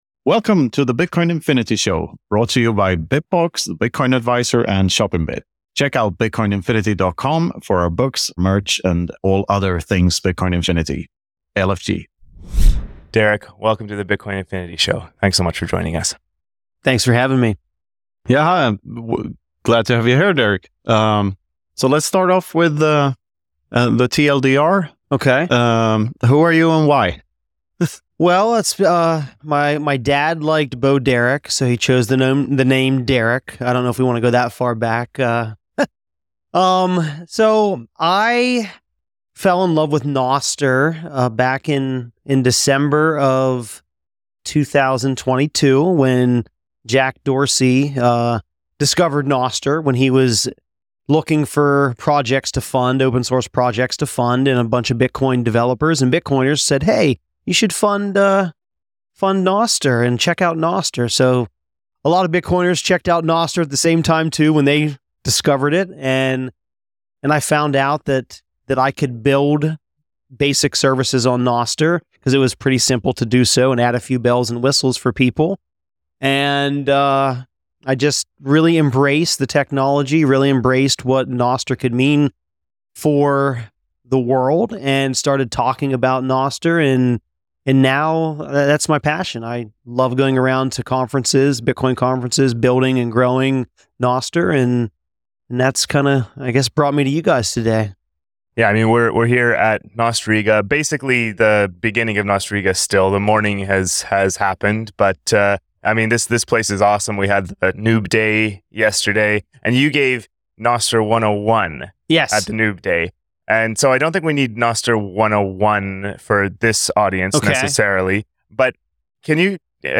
Recorded live in Riga on day one of Nostriga!